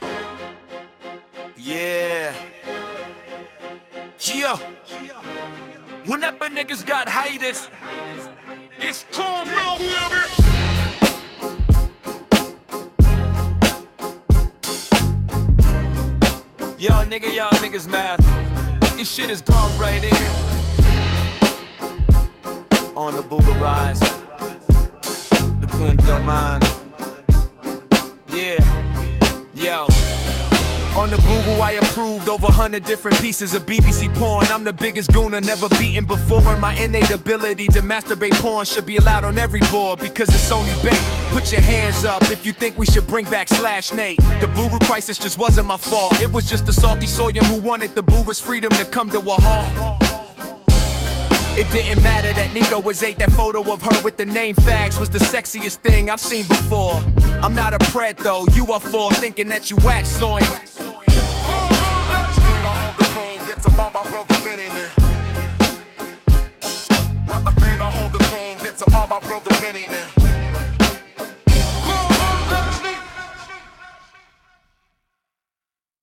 ITT make gemmy music with Suno AI
wrote a mustard rap but got bored so its short